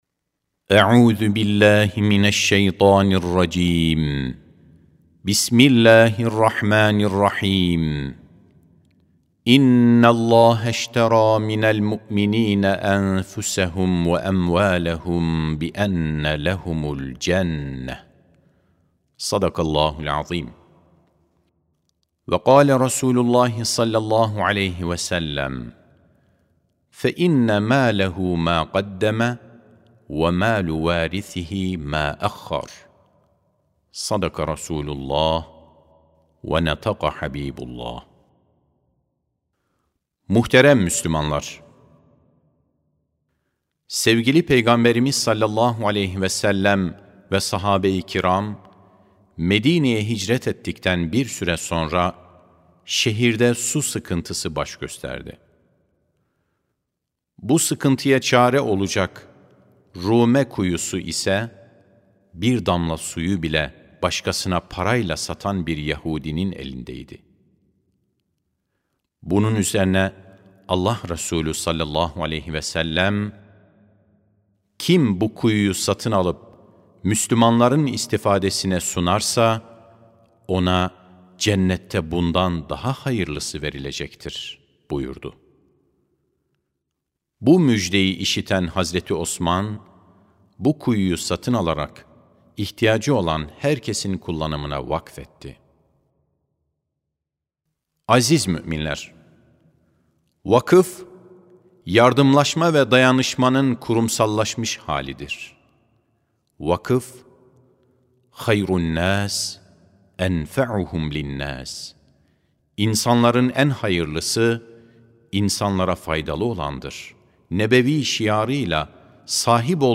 09.05.2025 Cuma Hutbesi: Vakıf: Yaratan'a Hürmet, Yaratılana Merhamet (Sesli Hutbe, Türkçe, İngilizce, İspanyolca, Fransızca, İtalyanca, Arapça, Rusça, Almanca)
Sesli Hutbe (Vakıf, Yaratan'a Hürmet, Yaratılana Merhamet).mp3